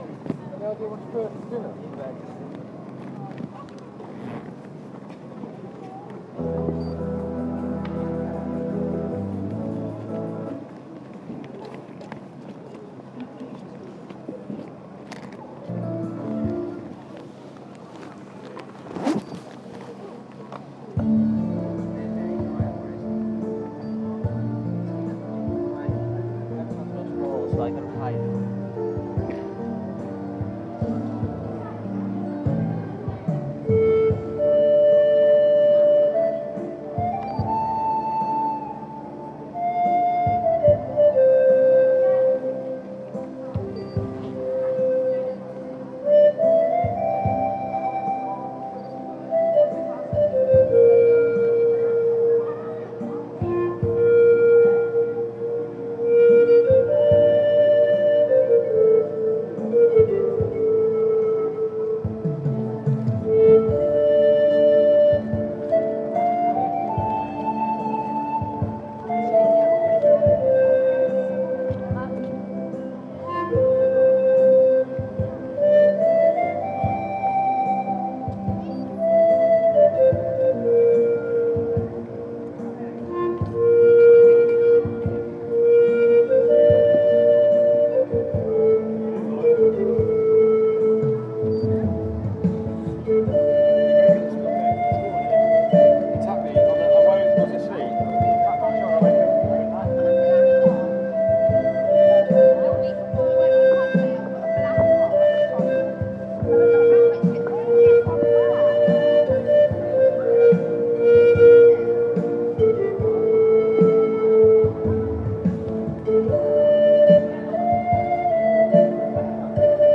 slower Panpipes last Sunday. Gets a bit repetitive after a while.